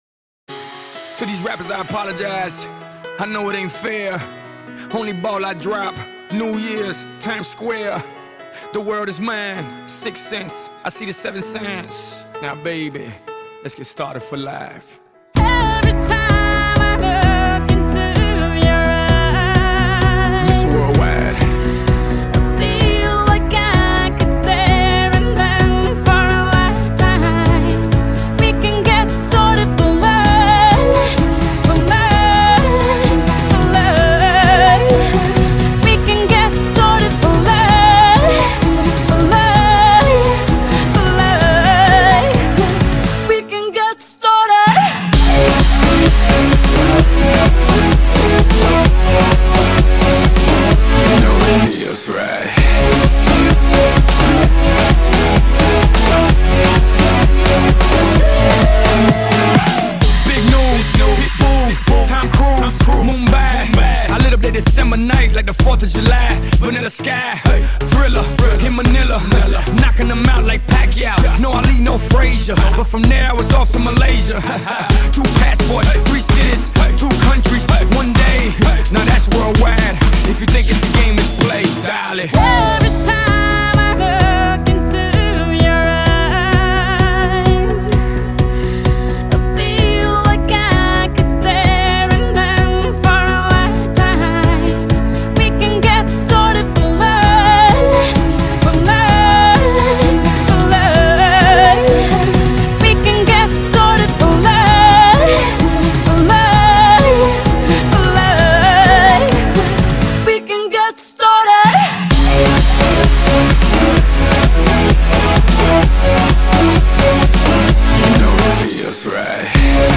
〽 ژانر Dance Pop